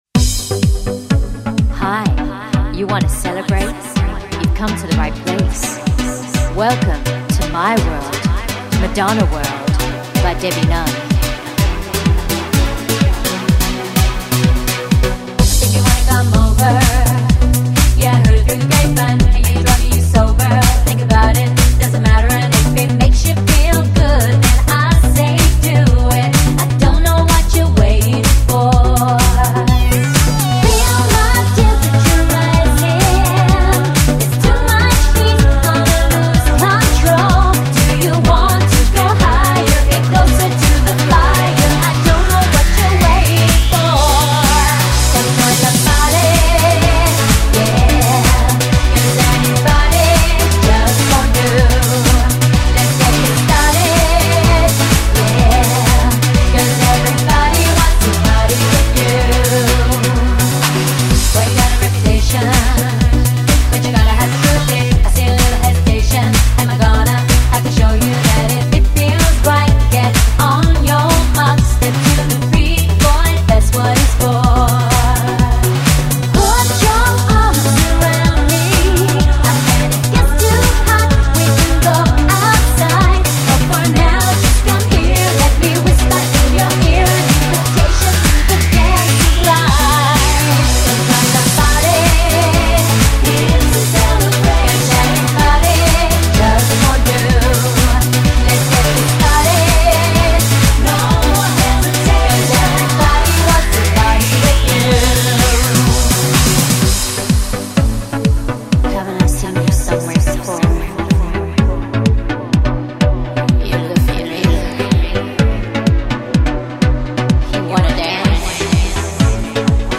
excellent 100% live vocals, powerful music arrangements